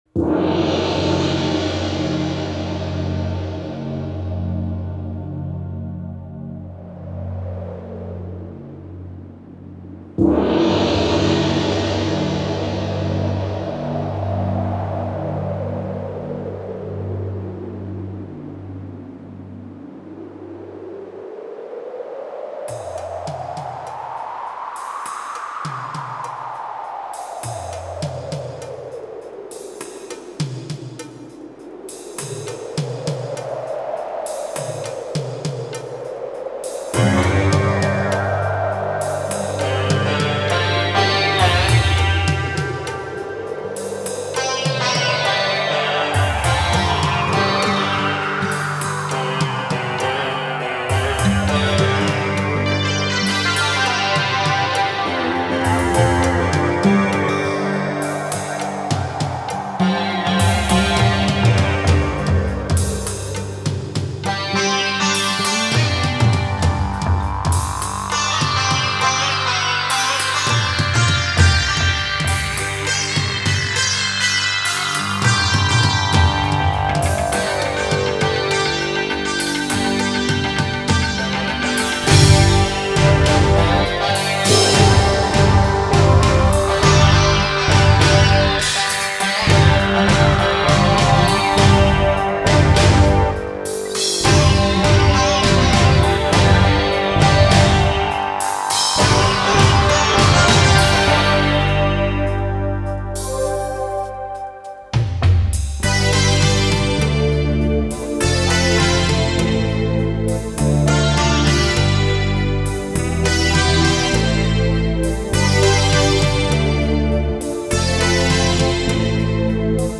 Русский Рок